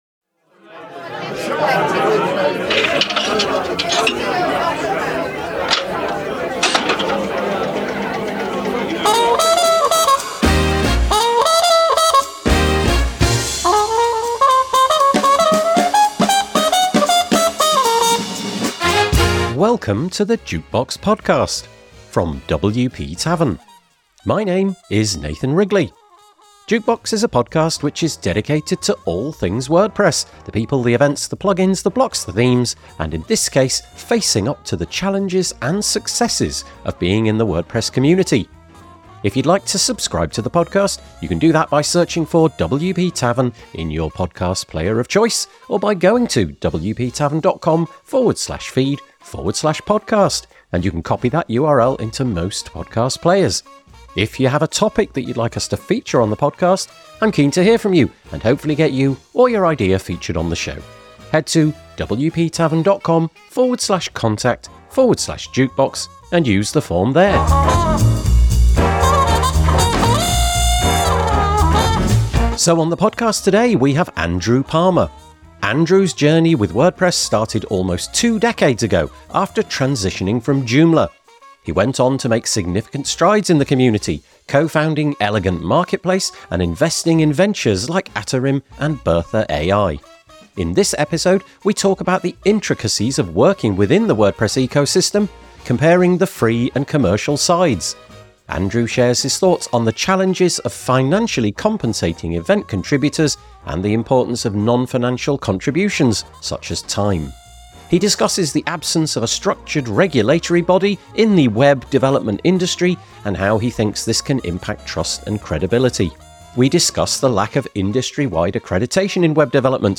The WP Tavern Jukebox is a podcast for the WordPress community. We interview people who are pushing change in how WordPress evolves. Plugins, Blocks, Themes, Community, Events, Accessibility and Diversity; we try to cover all the bases.